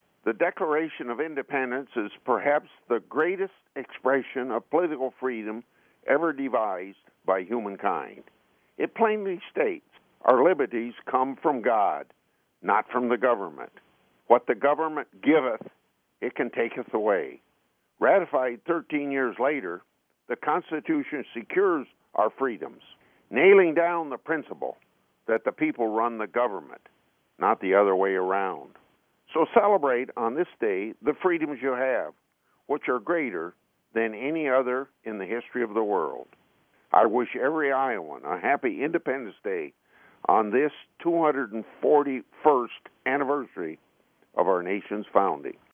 Fourth of July Statement 2017 (Long)